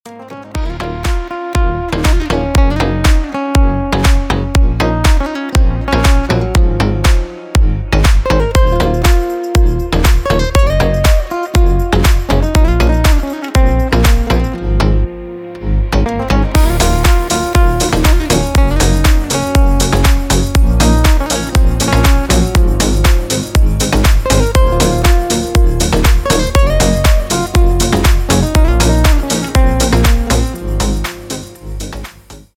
гитара
deep house
retromix
без слов
красивая мелодия
Cover